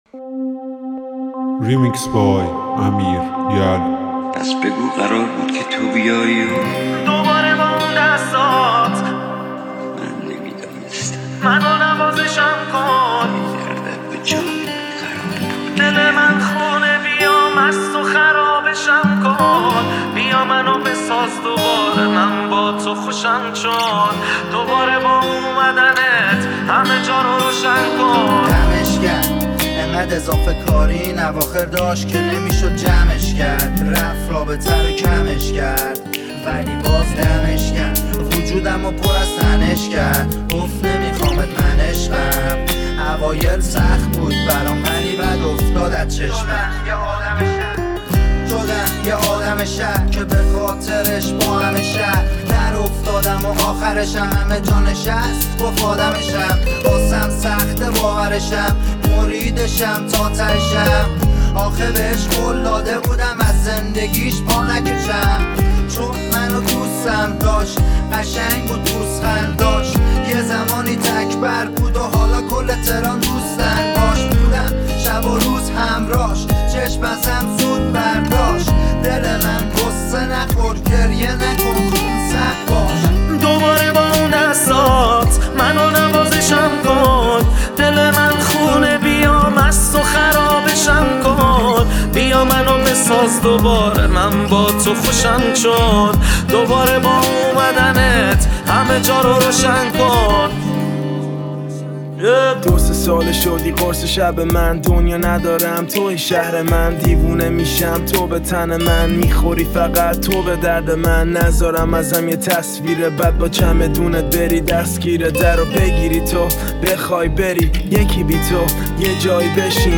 ریمیکس جدید دکلمه